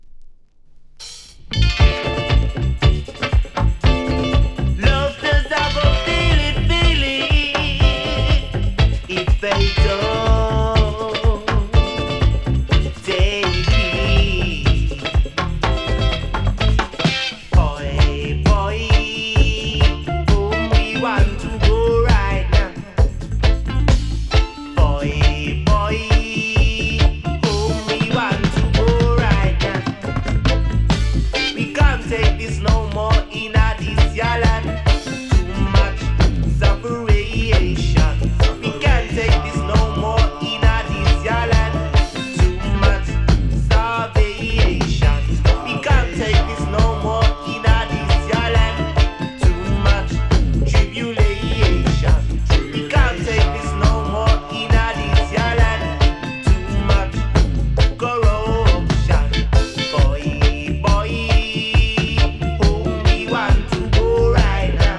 Reggae
Roots Reggae